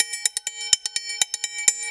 Index of /90_sSampleCDs/Houseworx/02 Percussion Loops